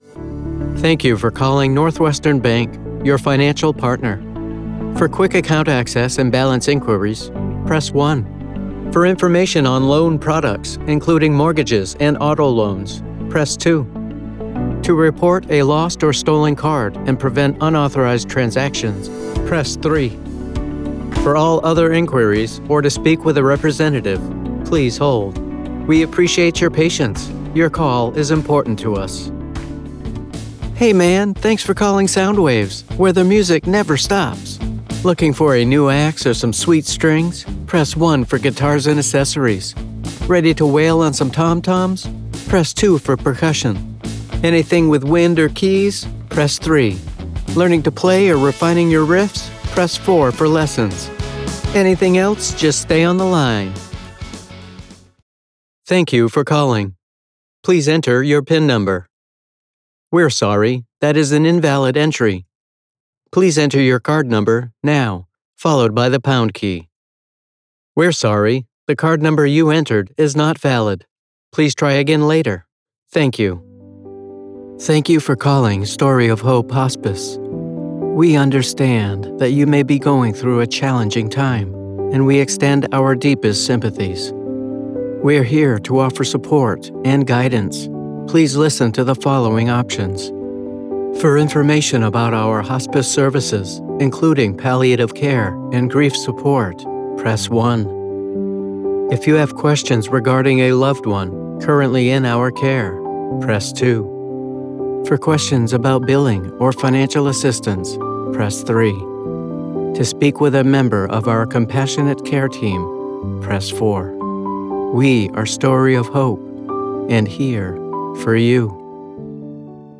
Male
I have a conversational tone that is natural, believable, and friendly.
Phone Greetings / On Hold
Various Phone Greetings/On Hold
0812Telephony_2_Mixdown.mp3